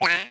monster_open.mp3